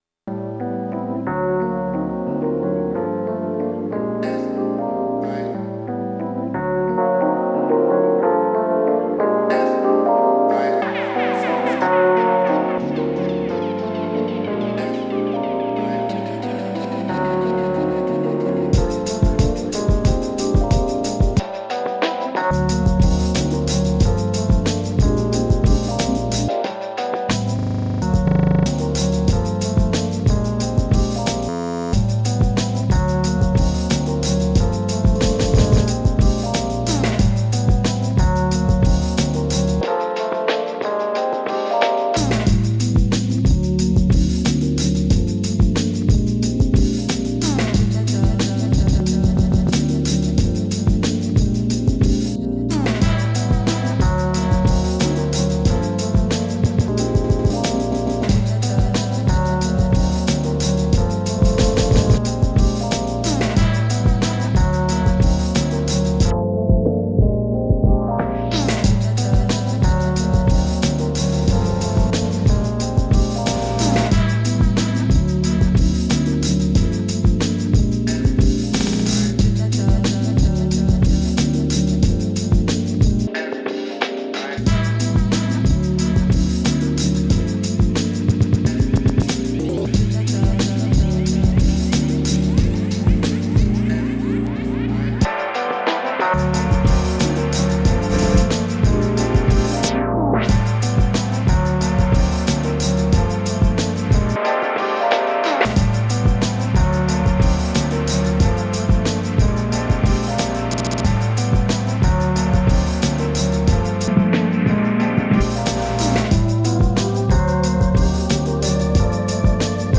Dance House Techno